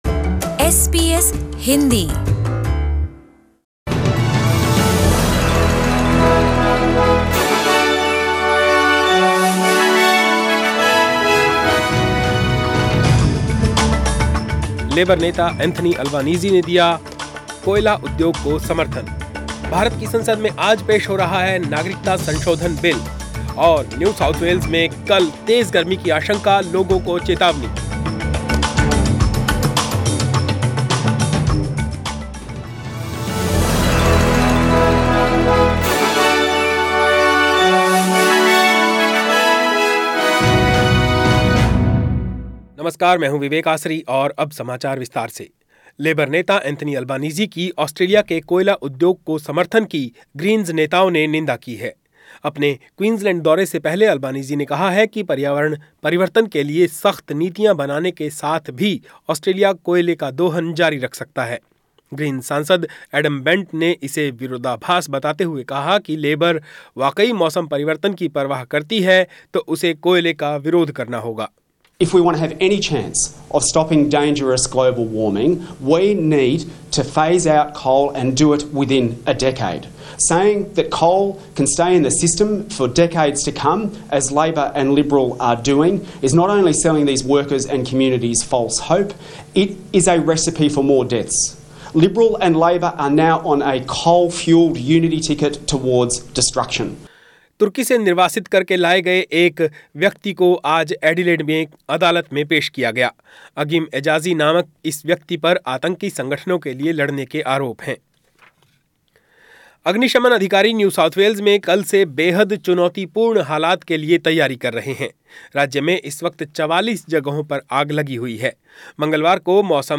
News in Hindi